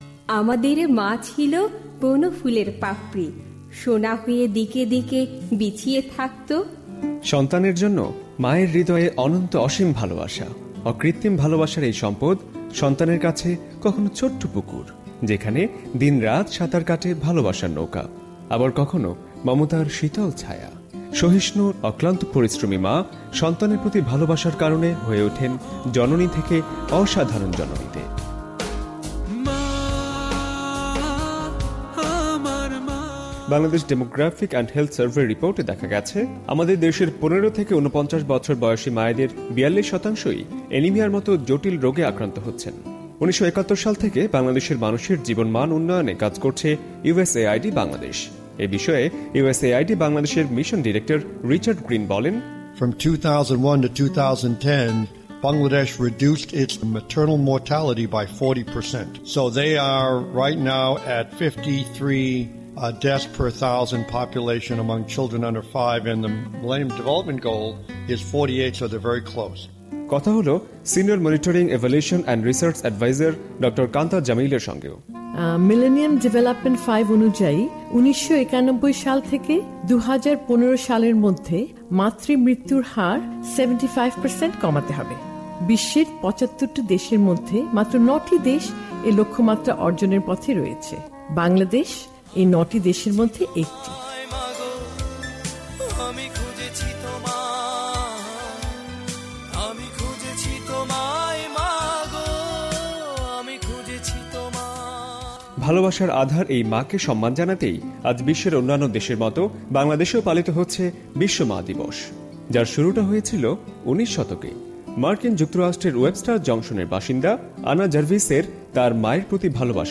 ভয়েস অফ আমেরিকা ও যুক্তরাষ্ট্র উন্নয়ন সংস্থা USAIDর যৌথ উদ্যোগে, এবং ঢাকা রিপোর্টিং সেন্টারের সহযোগিতায় প্রণীত বিশেষ প্রতিবেদন – ‘স্বাস্থ্য কথা’। আজ বিশ্ব ‘মা দিবস’।